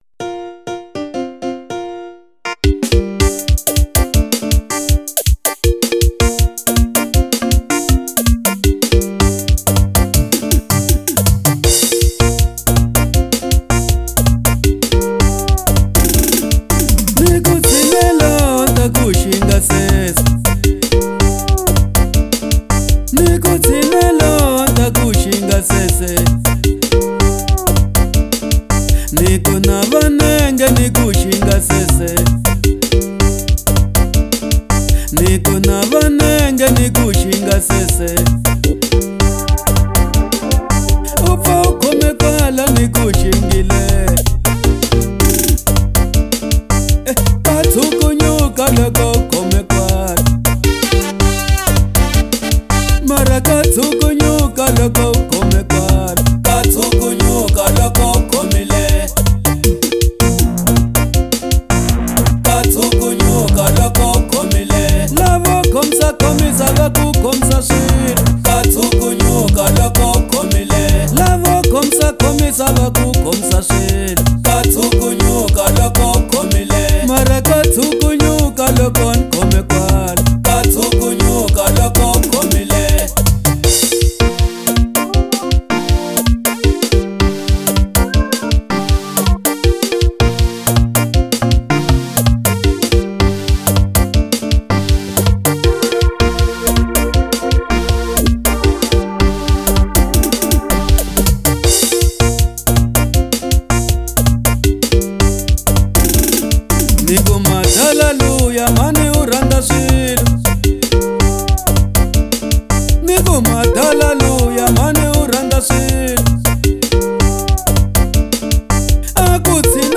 04:47 Genre : Xitsonga Size